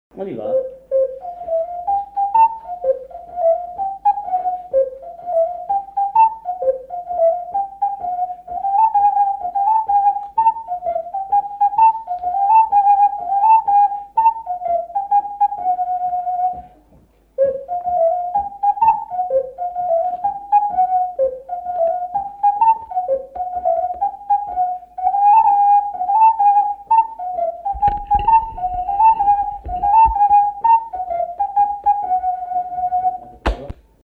Couplets à danser
branle : avant-deux
Répertoire de chansons populaires et traditionnelles
Pièce musicale inédite